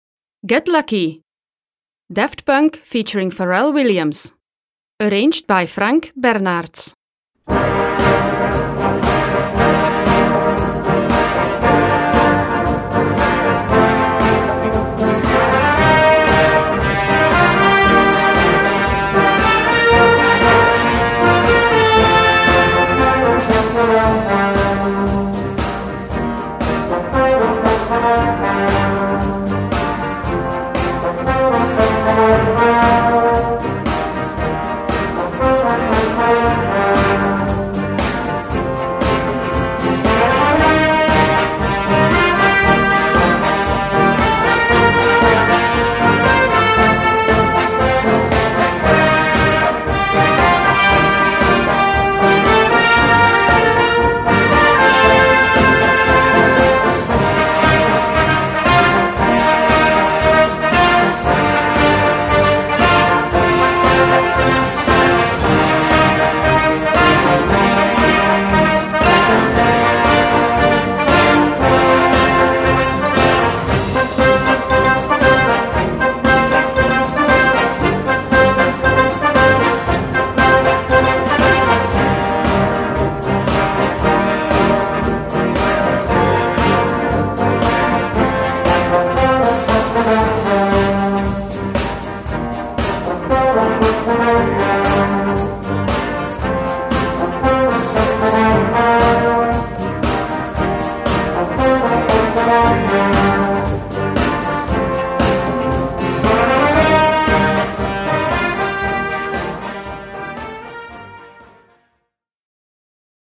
A4 Besetzung: Blasorchester PDF